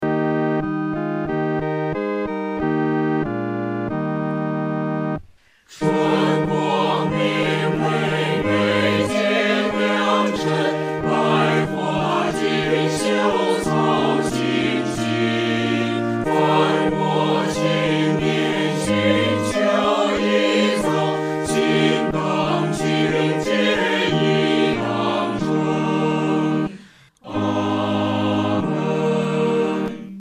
合唱
四声
本首圣诗由网上圣诗班录制